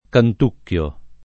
DOP: Dizionario di Ortografia e Pronunzia della lingua italiana
canticchiare